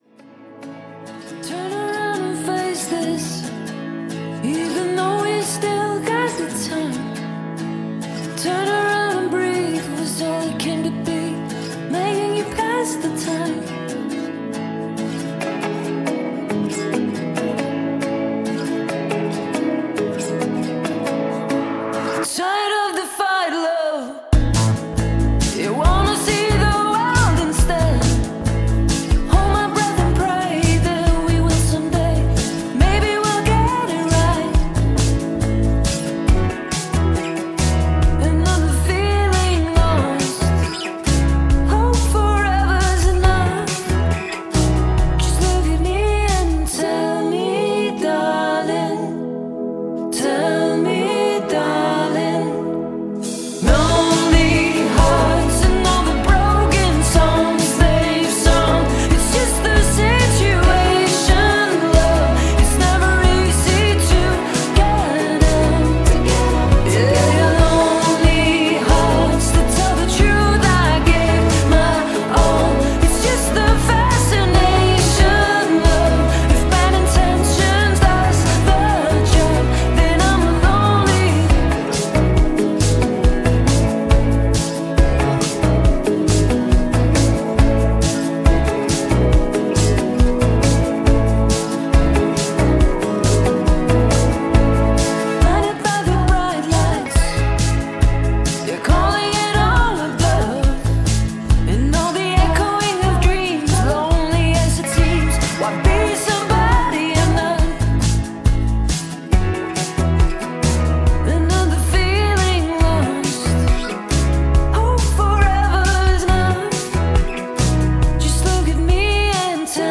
• Indie
• Pop
• Rock
• Singer/songwriter